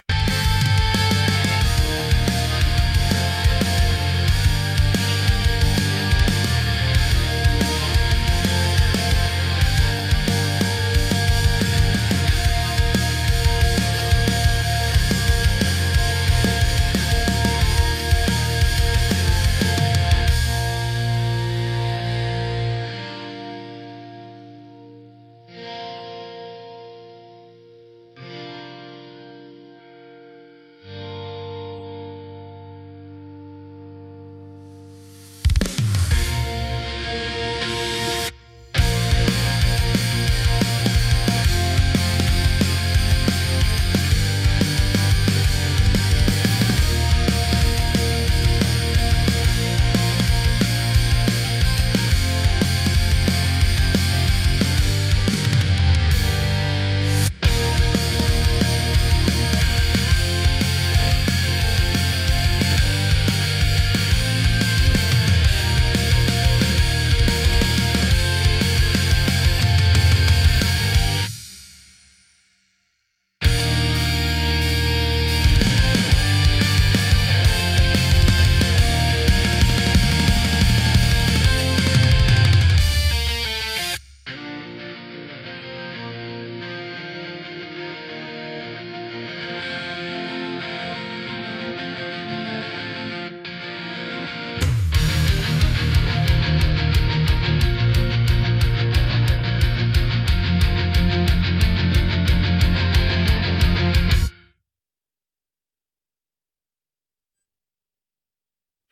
▶ TopMediai 音楽ツールで「冬のラブソング」のインストを分離：
ai-song-Instrumental.mp3